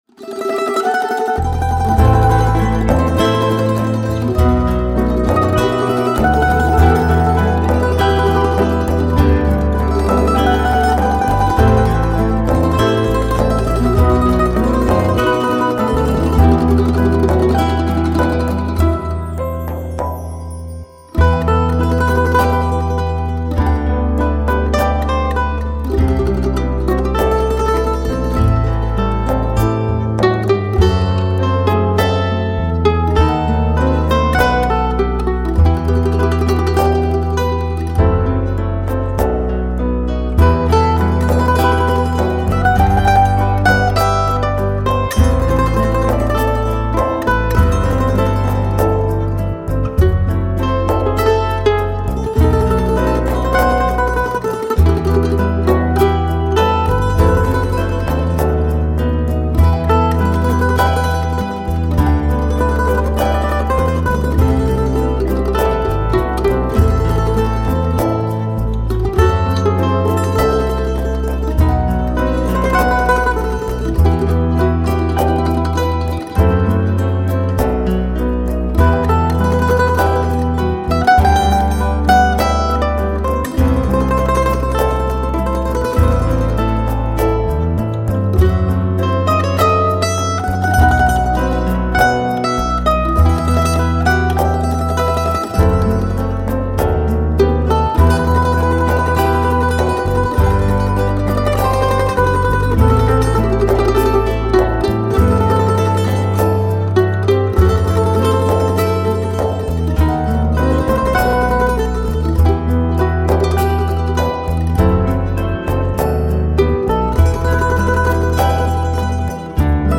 tema dizi müziği, duygusal huzurlu rahatlatıcı fon müziği.